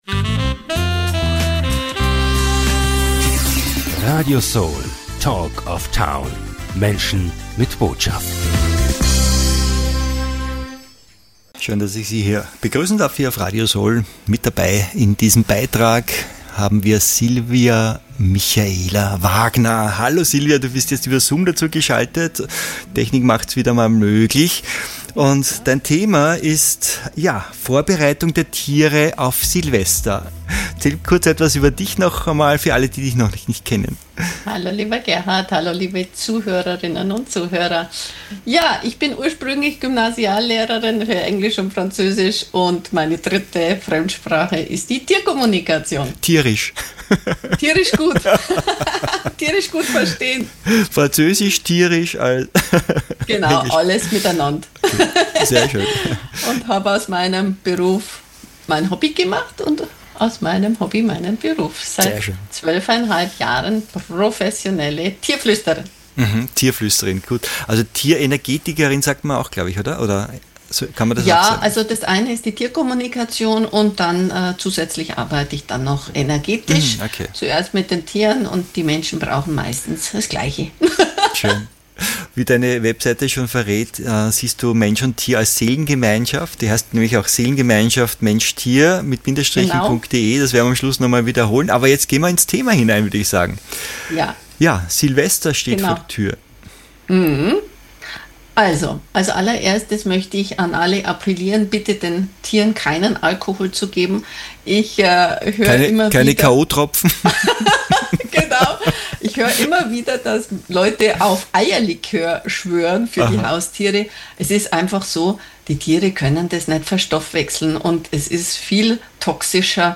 In diesem berührenden und informativen Interview